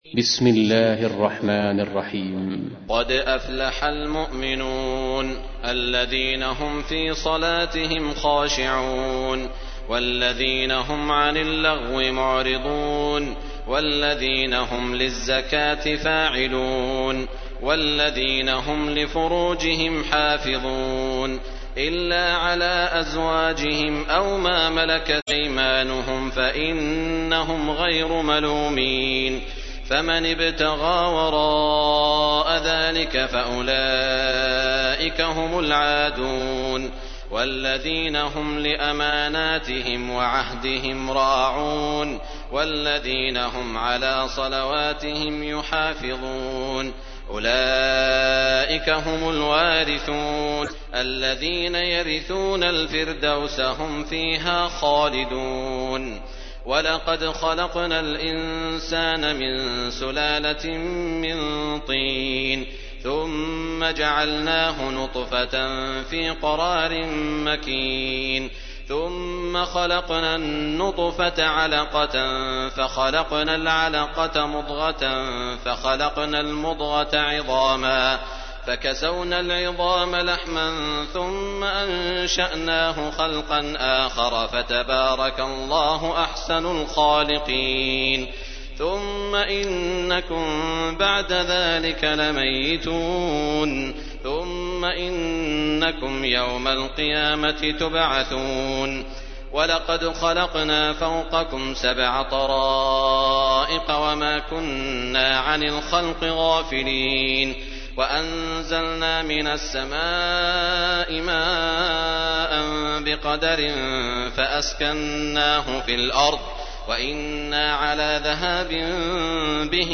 تحميل : 23. سورة المؤمنون / القارئ سعود الشريم / القرآن الكريم / موقع يا حسين